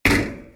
Metalic Impact.wav